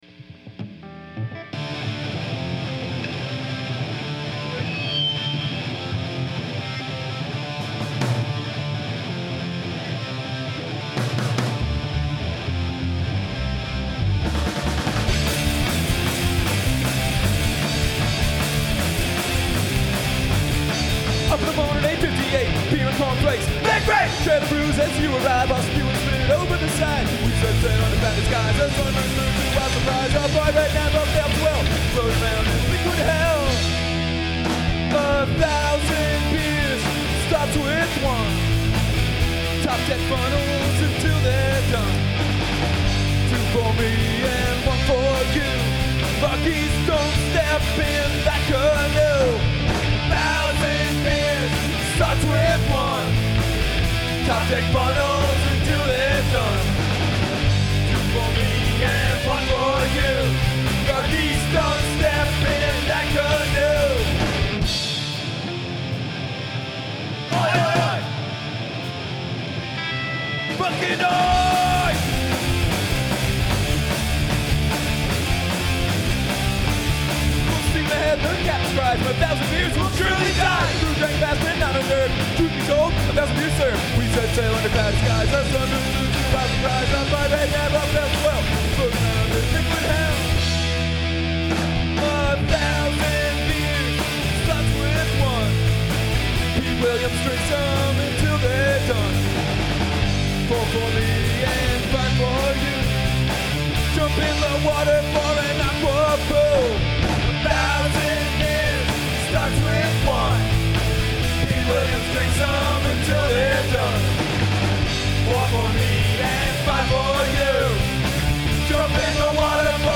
Recorded live - Dec '06